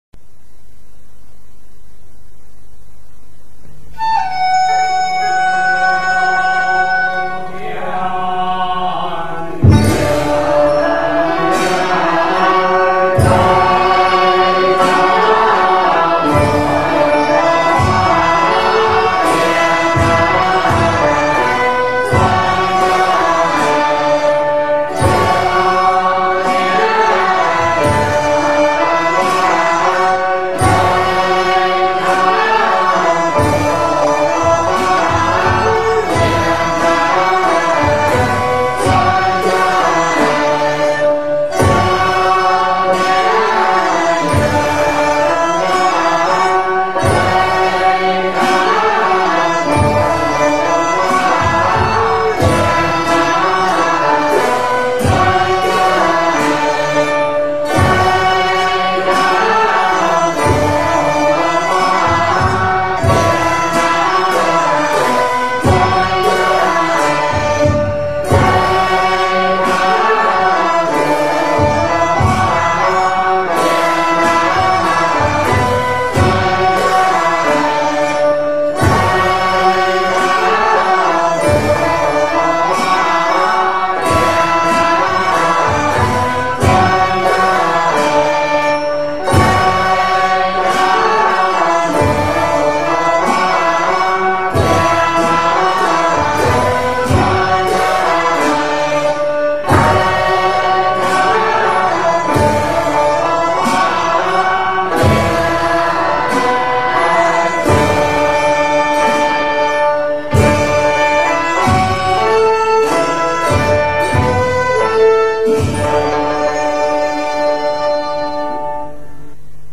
中国道教音乐-浙江韵-天尊板